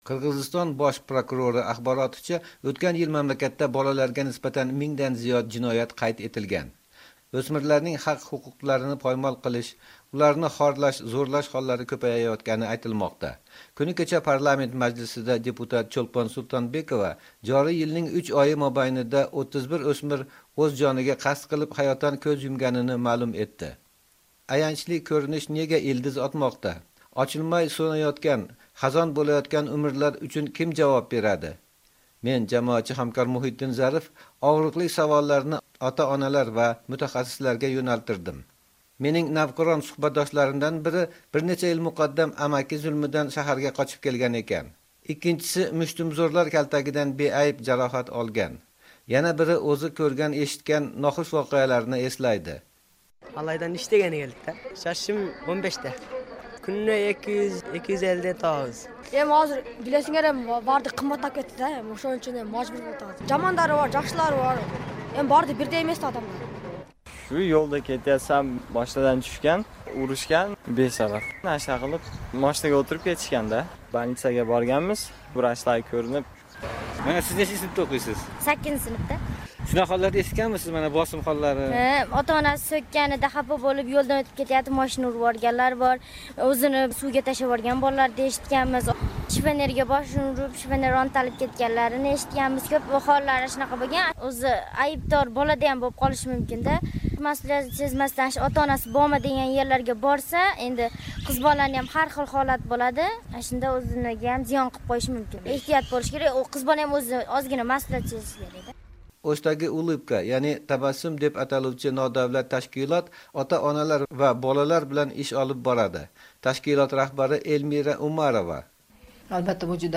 Bolalarni kim himoya qiladi? Qirg'izistondan reportaj